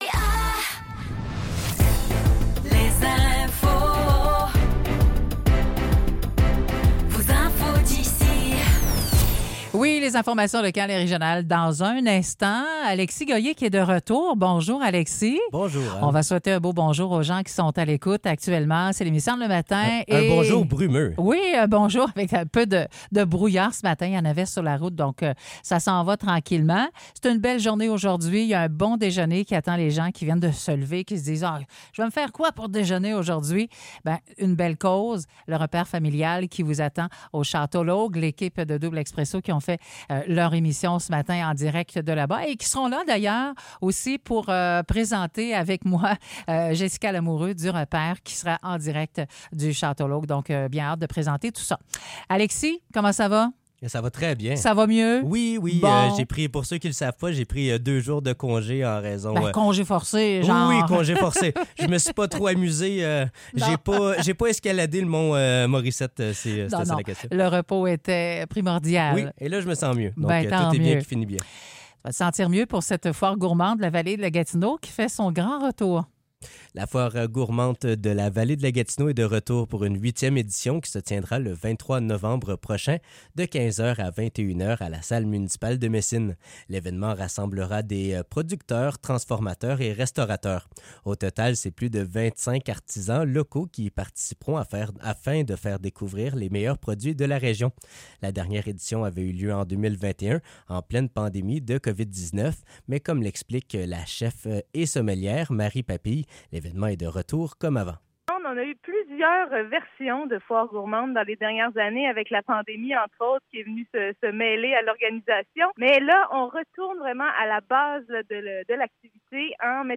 Nouvelles locales - 20 novembre 2024 - 9 h